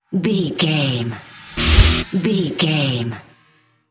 Aeolian/Minor
D
drums
electric guitar
bass guitar
hard rock
aggressive
energetic
intense
nu metal
alternative metal